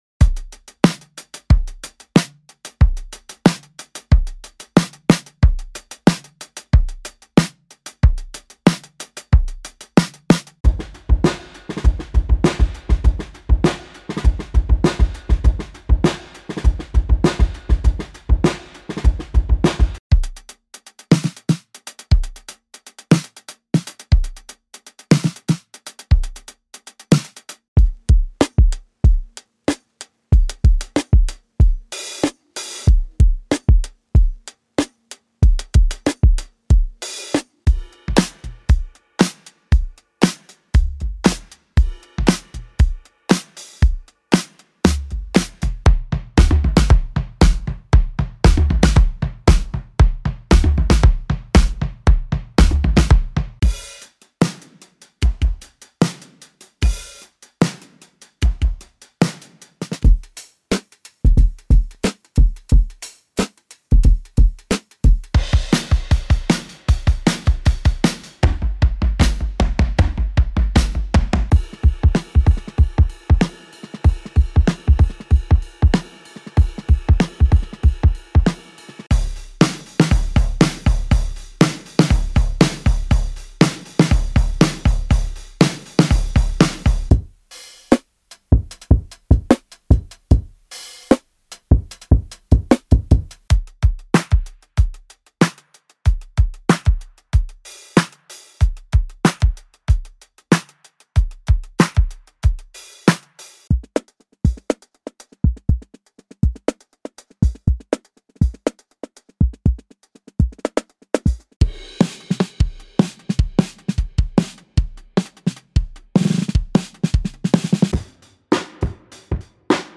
どんなトラックやミックスも、よりハードにヒットするウルトラデッド・ヴィンテージ・ドラムが魂を吹き込む
preset_playthrough[412].mp3